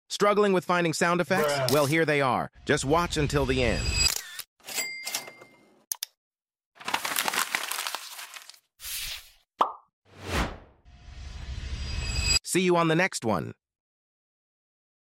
Here are some sound effects sound effects free download
Here are some sound effects essentials for your video editing journey!